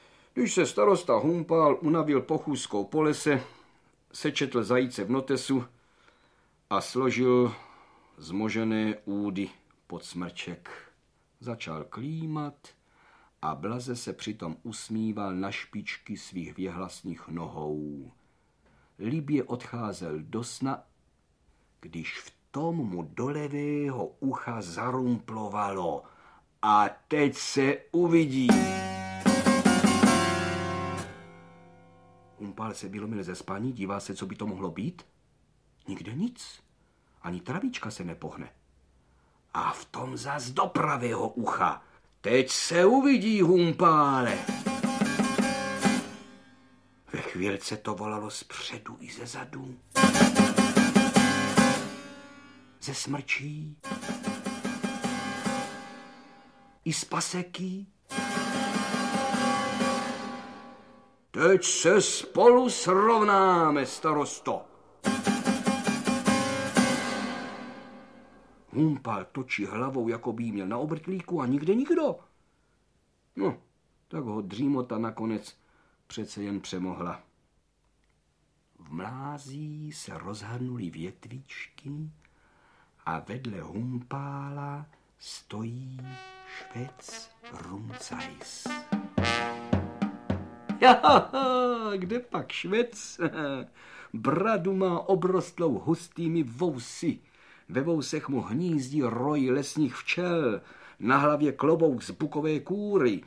O Rumcajsovi, Mance a Cipískovi audiokniha
o-rumcajsovi-mance-a-cipiskovi-audiokniha